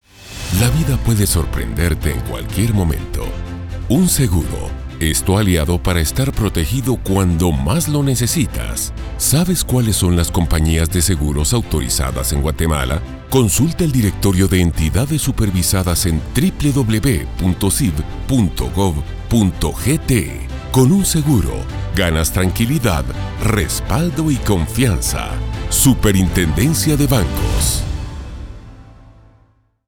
Anuncios en Radio